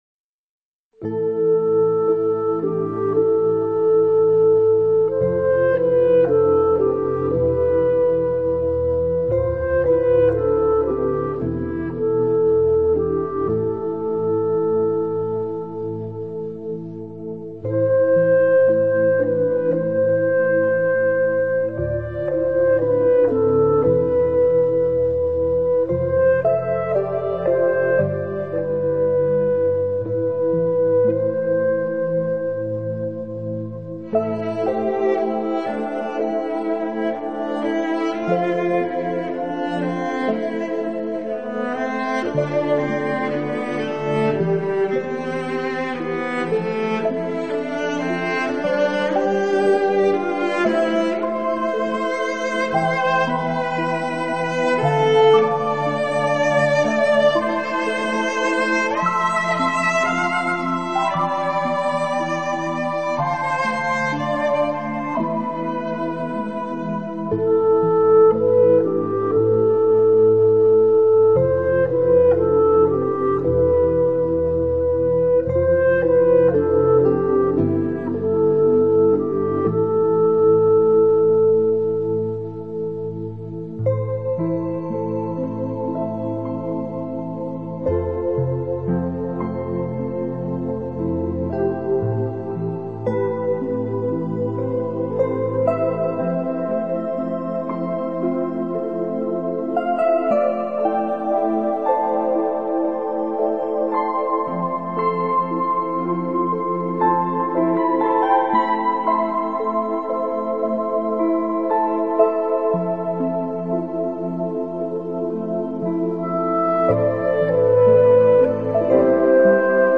音乐元素和风格，或许只有这种神奇的民族音乐才可以展现原始的宏伟和苍凉，